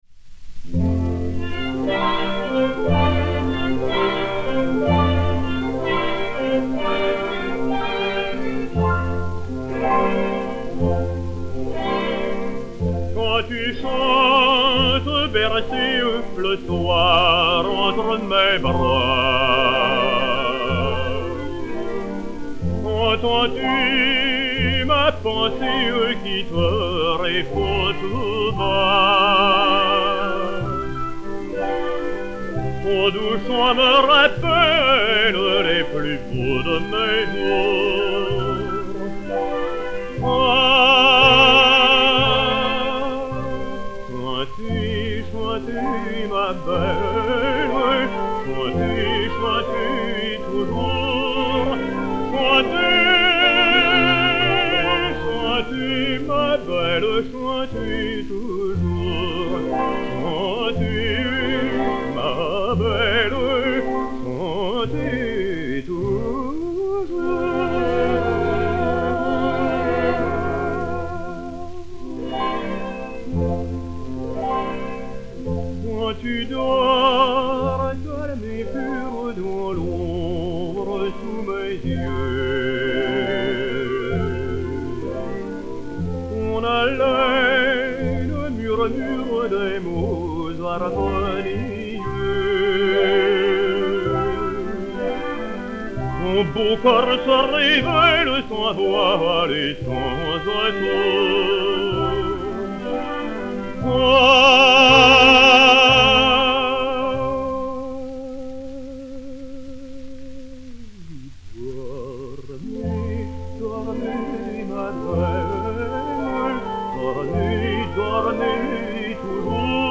Mélodie, poésie de Victor Marie HUGO (Besançon, Doubs, 26 février 1802 [7 ventôse an X]* – Paris 16e, 22 mai 1885*), musique de Charles GOUNOD (1857).
Ninon Vallin, soprano, et Orchestre